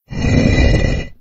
end_of_level.ogg